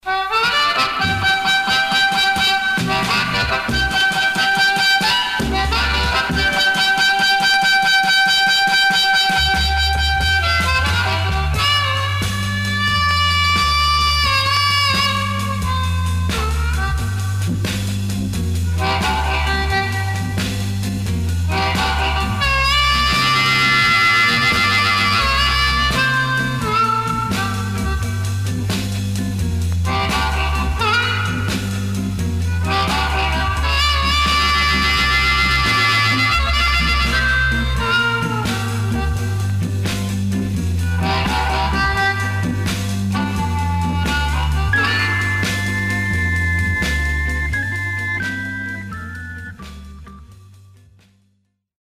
Stereo/mono Mono
R&B Instrumental